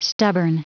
Prononciation du mot stubborn en anglais (fichier audio)
Prononciation du mot : stubborn